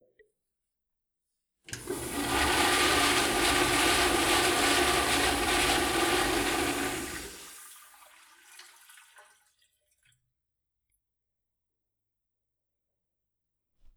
Cisterna de un váter
Limpieza de un sanitario.